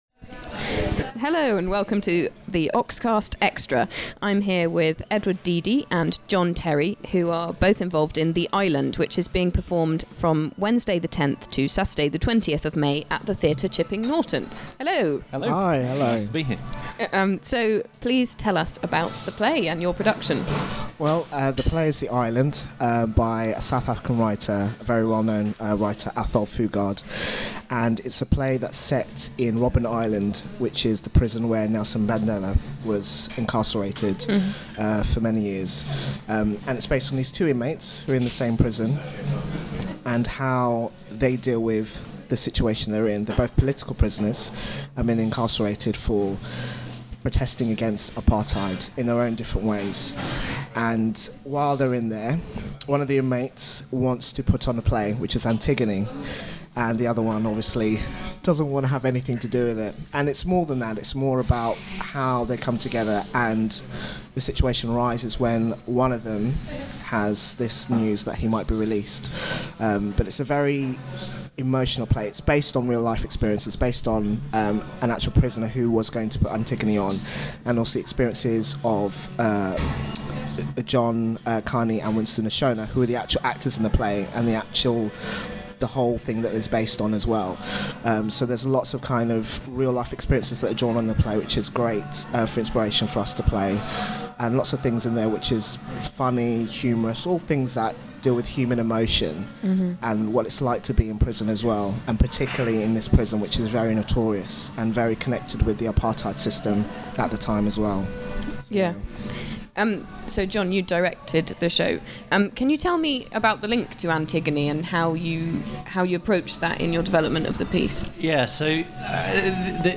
The-Island-Interview_mixdown.mp3